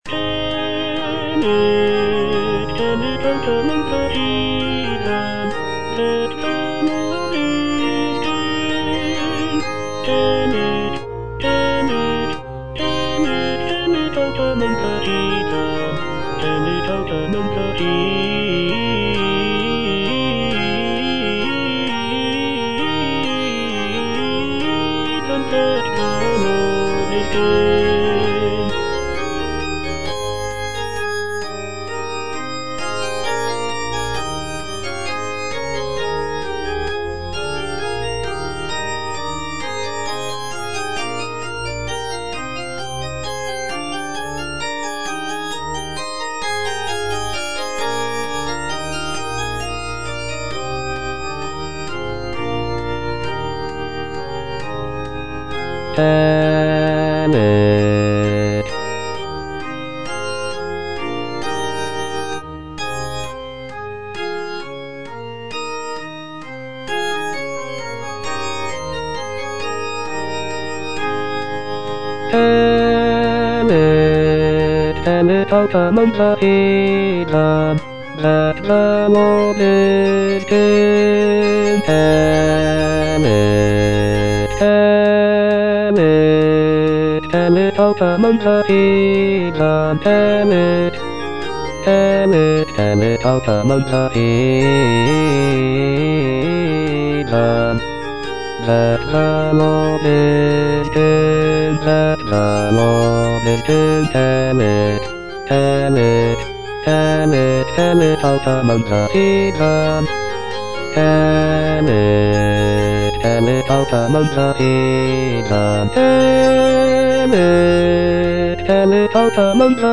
Choralplayer playing O come, let us sing unto the Lord - Chandos anthem no. 8 HWV253 (A = 415 Hz) by G.F. Händel based on the edition CPDL #09622
G.F. HÄNDEL - O COME, LET US SING UNTO THE LORD - CHANDOS ANTHEM NO.8 HWV253 (A = 415 Hz) Tell it out among the heathen - Bass (Voice with metronome) Ads stop: auto-stop
The use of a lower tuning of A=415 Hz gives the music a warmer and more resonant sound compared to the standard tuning of A=440 Hz.